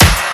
INSTCLAP06-L.wav